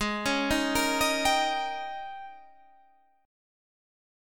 AbM7sus2sus4 chord